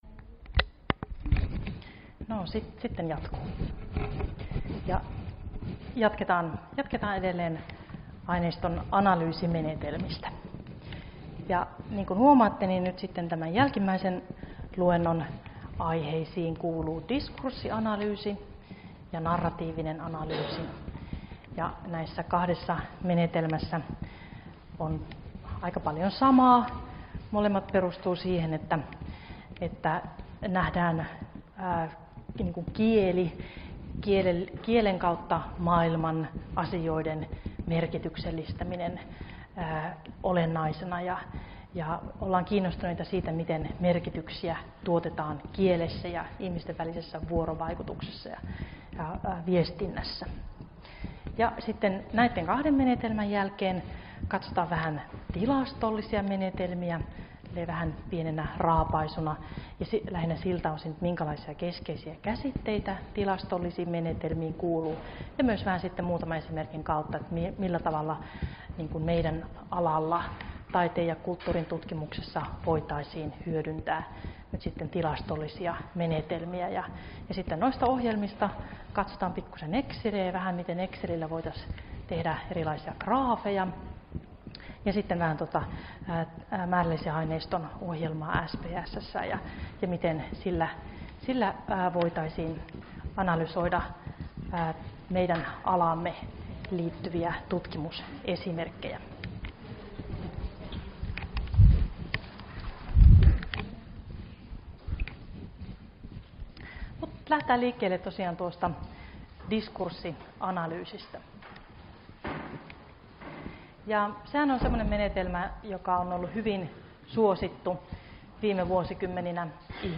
Luento 9 - Aineiston analyysimenetelmiä 2 — Moniviestin